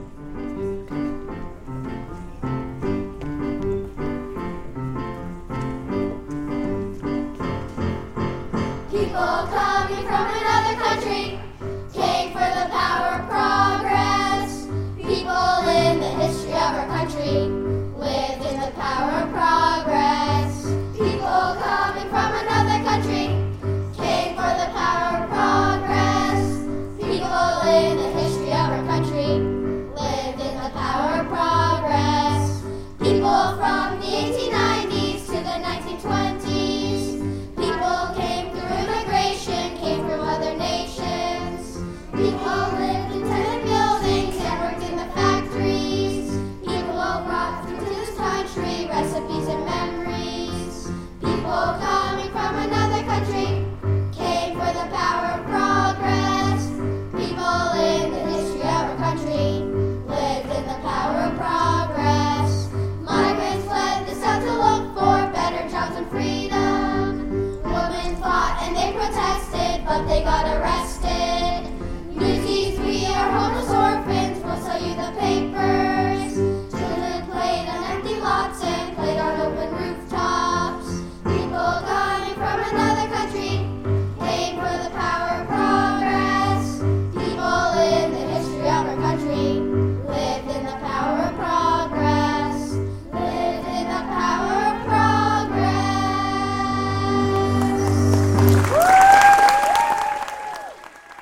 Their performance began with the singing of the theme song “Power of Progress”.